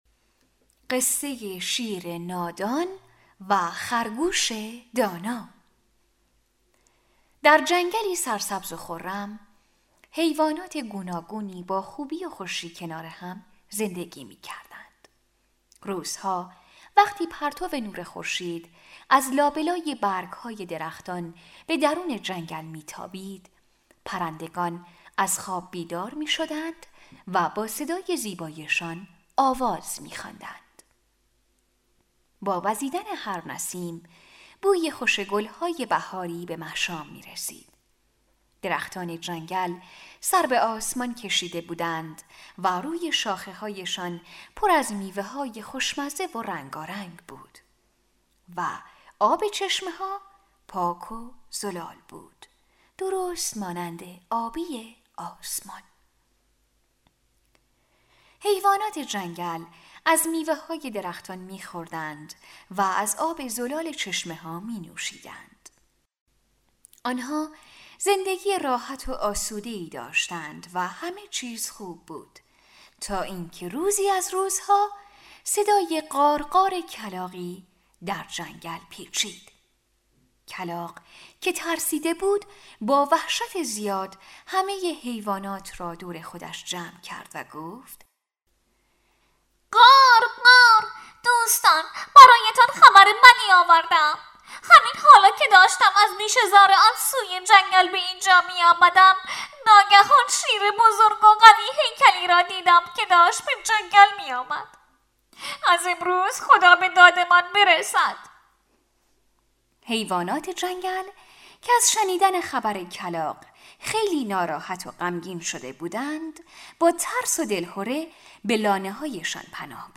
قصه های کودکانه